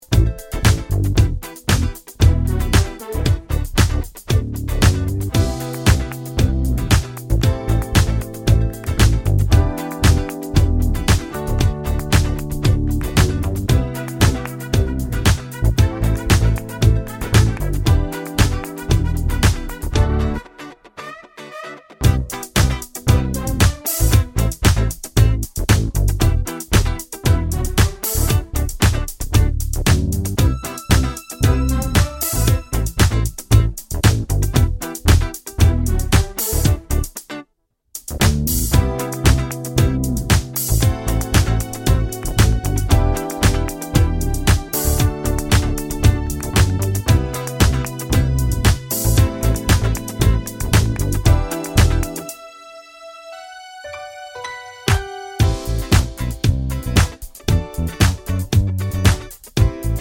Clean Version R'n'B / Hip Hop 3:50 Buy £1.50